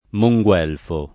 Monguelfo [ mo jgU$ lfo ]